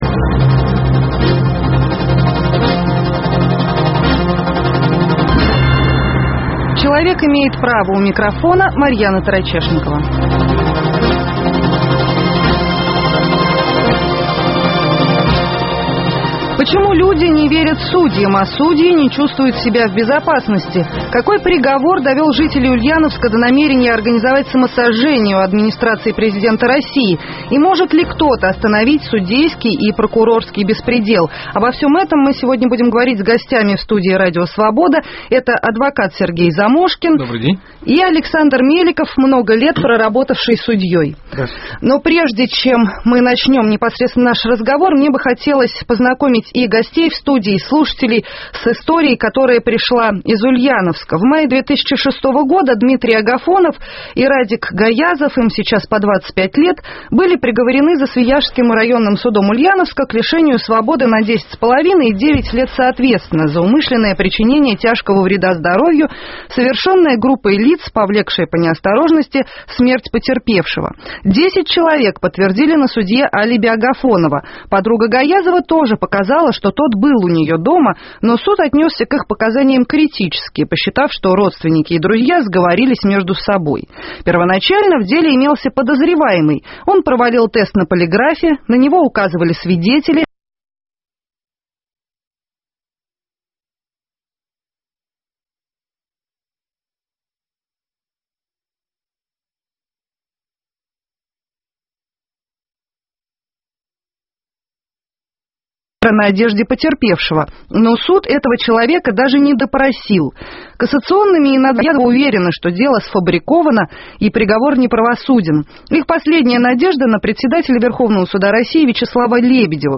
Почему люди не верят судьям, а судьи не чувствуют себя в безопасности? Какой приговор довел жителей Ульяновска до намерения организовать самосожжение на Красной площади и может ли кто-то остановить судейский и прокурорский беспредел? В студии Радио Свобода